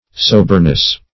Soberness \So"ber*ness\, n.